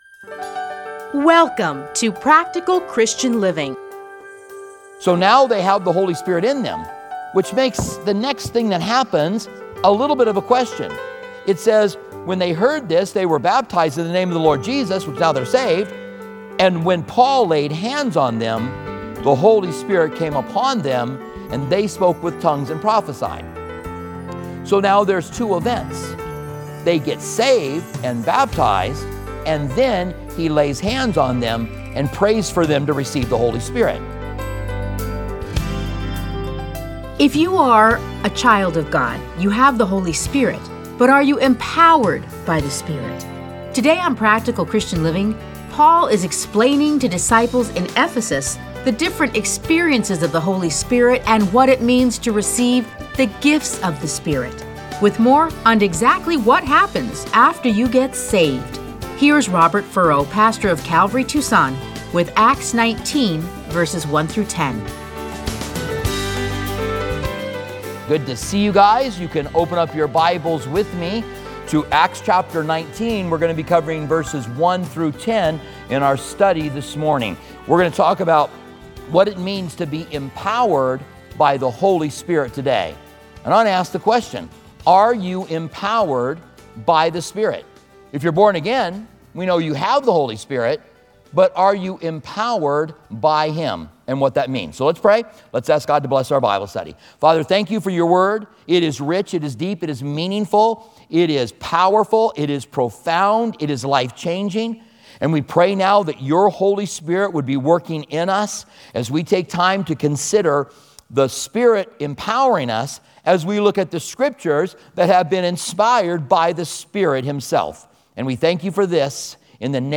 Listen to a teaching from Acts 19:1-10.